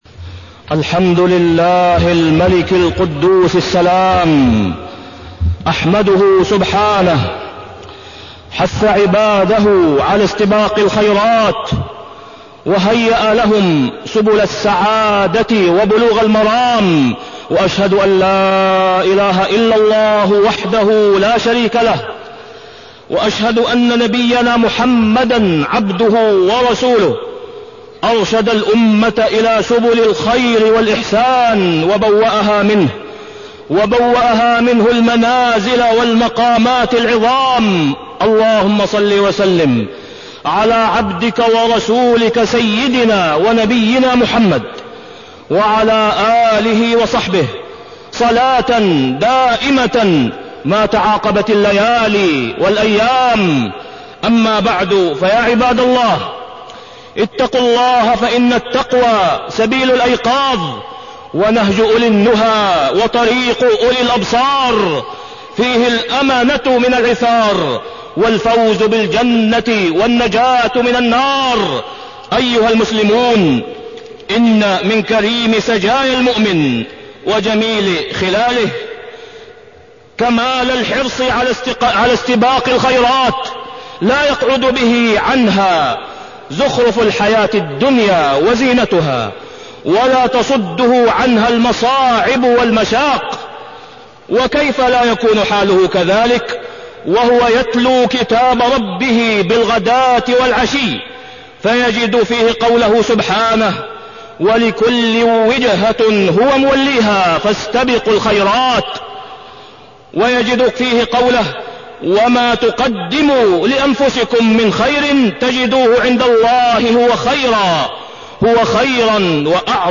تاريخ النشر ٢١ جمادى الآخرة ١٤٢٣ هـ المكان: المسجد الحرام الشيخ: فضيلة الشيخ د. أسامة بن عبدالله خياط فضيلة الشيخ د. أسامة بن عبدالله خياط الأعمال الخيرية